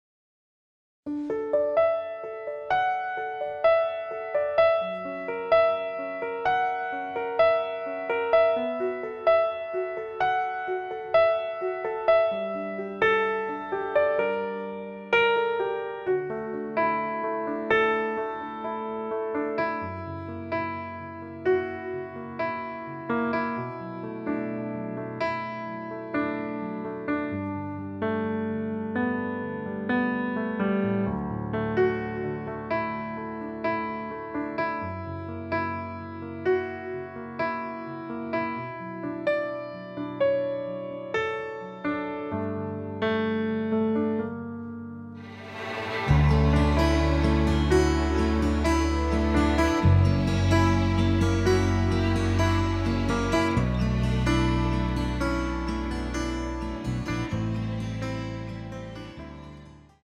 앞부분30초, 뒷부분30초씩 편집해서 올려 드리고 있습니다.
중간에 음이 끈어지고 다시 나오는 이유는
공식 MR